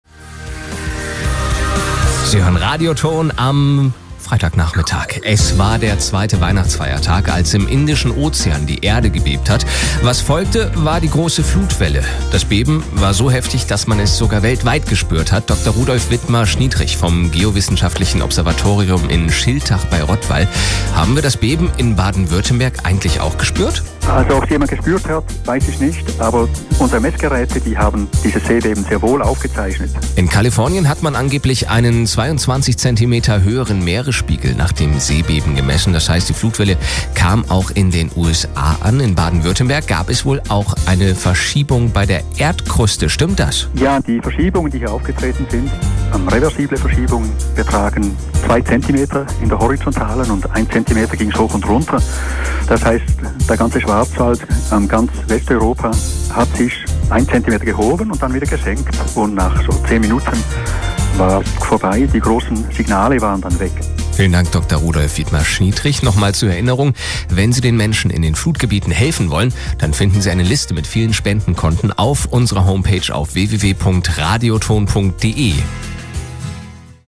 Im Rundfunk...